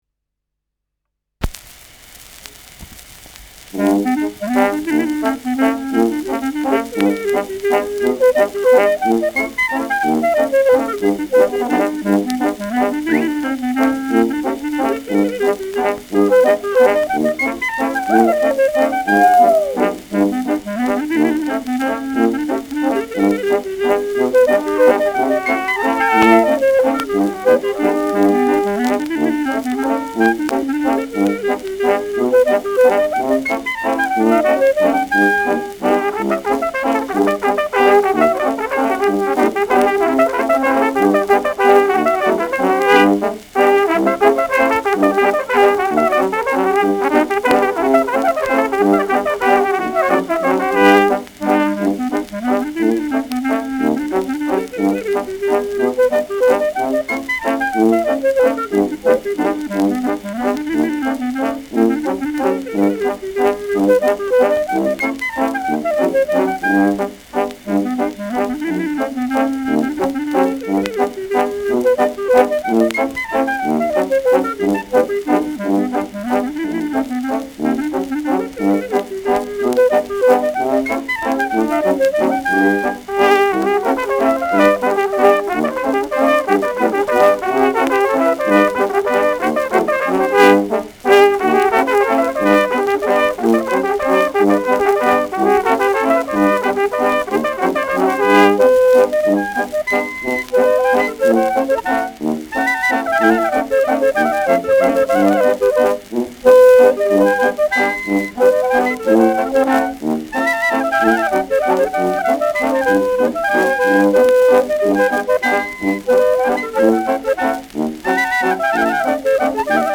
Schellackplatte
Tonrille: leichter Abrieb
leichtes Knistern